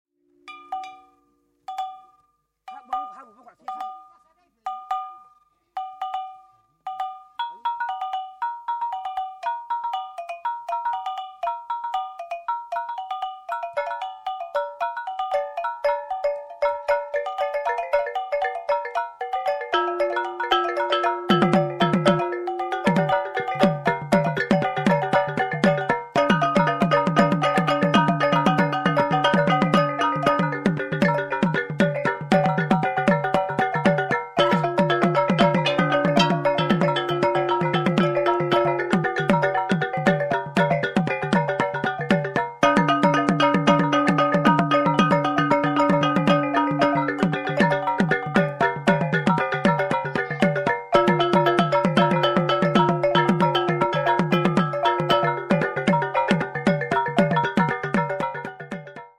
and traditional dances reflect this.